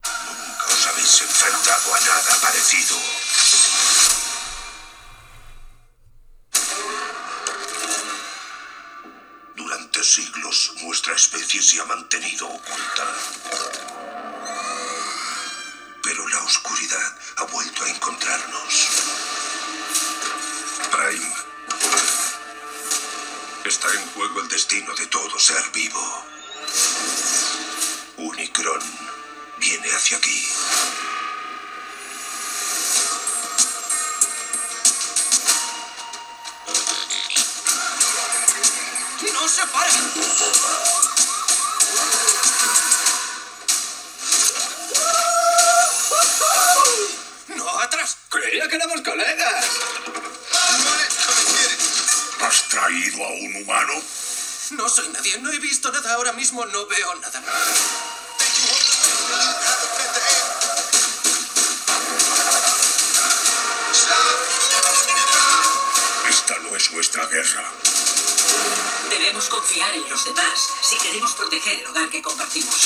En cuanto al apartado sonoro, este MSI Summit E16 Flip 2023 cuenta con dos altavoces rectangulares tipo membrana, orientados hacia abajo en las esquinas inferiores. Estos altavoces no parecen dar la talla en lo que a equilibrio de frecuencias se refiere, ya que tenemos ausencia de graves y agudos bastante acentuados a máximo volumen. Volumen que reconocemos, es bastante elevado, pero que para un equipo de este tipo esperábamos al menos 4 altavoces o como mínimo un woofer.